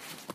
pickup.ogg